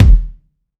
KICK.51.NEPT.wav